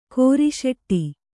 ♪ kōri śeṭṭi